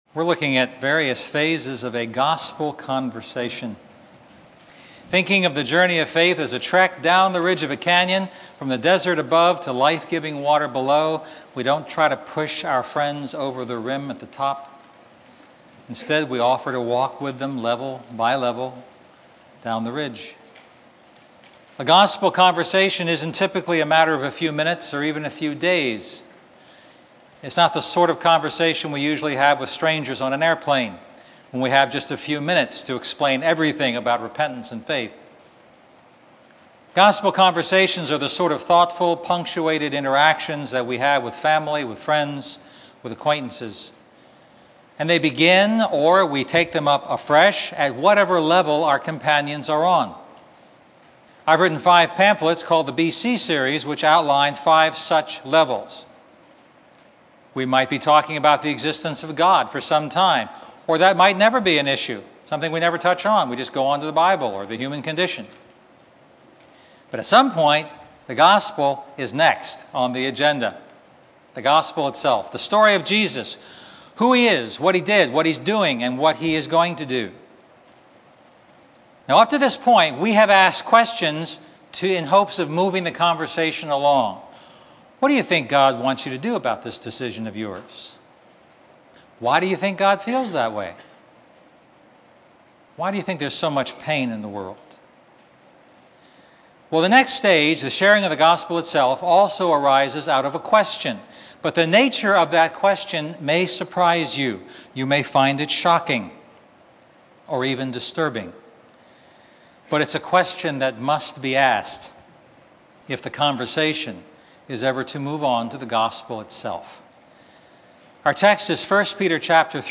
A message from the series "A Gospel Conversation."